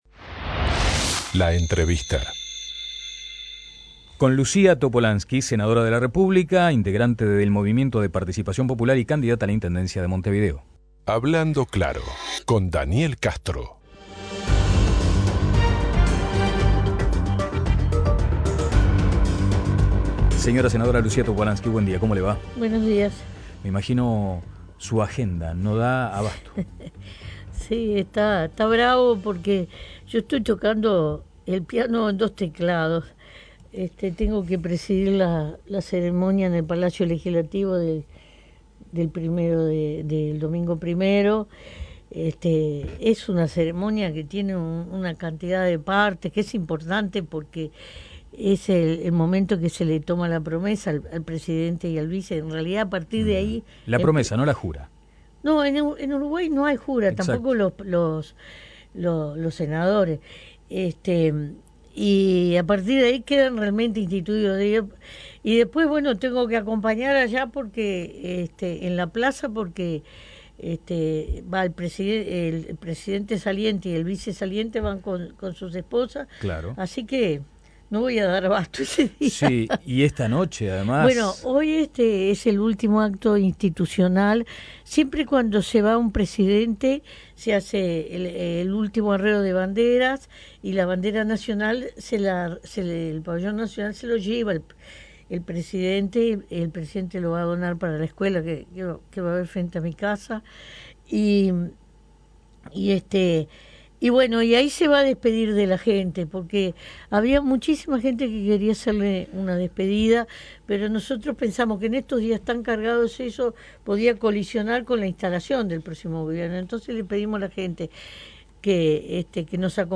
Luc�a Topolansky en estudios